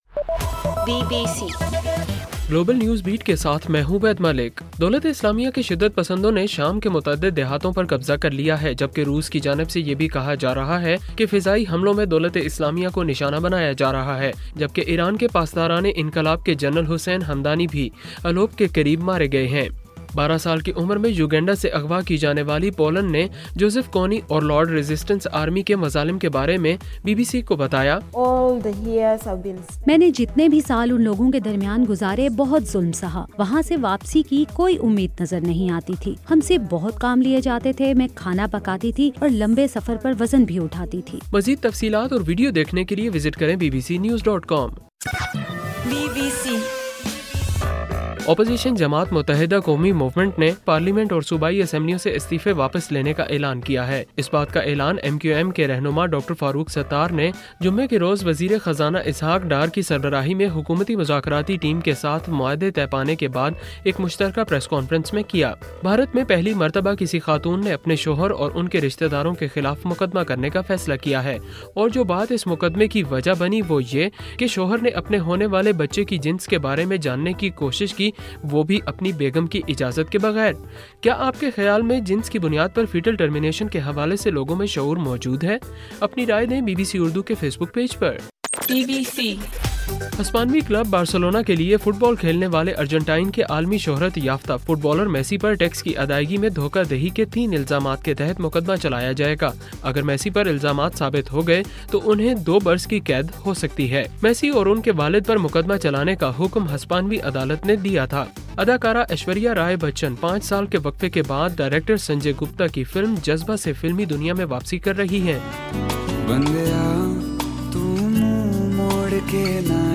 اکتوبر 9: رات 9 بجے کا گلوبل نیوز بیٹ بُلیٹن